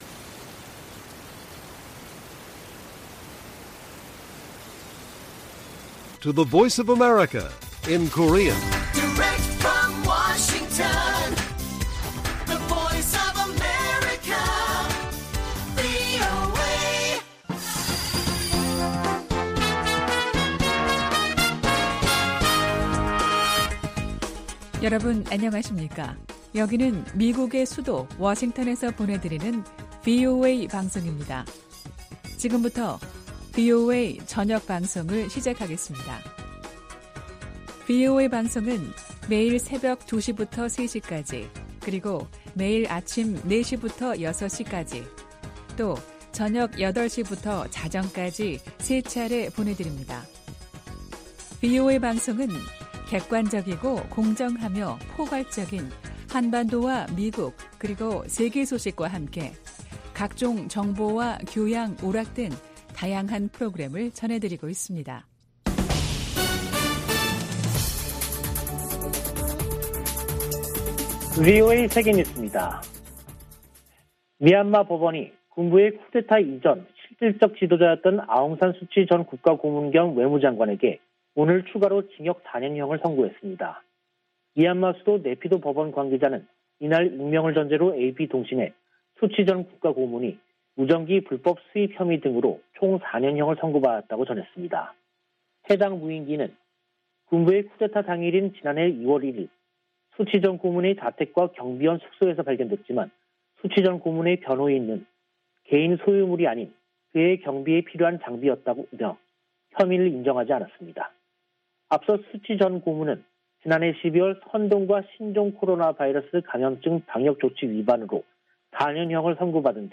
VOA 한국어 간판 뉴스 프로그램 '뉴스 투데이', 2022년 1월 10일 1부 방송입니다. 미국 등 5개국이 북한의 미사일 발사에 대한 유엔 안보리 협의를 요청했다고 미 국무부가 밝혔습니다. 북한 극초음속 미사일을 방어하기 위해서는 초기 탐지 능력이 중요하다고 미국의 전문가들은 지적했습니다. 지난해 10월 북한에 들어간 지원 물자가 소독 등을 마치고 정부 배급소에 도착했다고 유엔이 확인했습니다.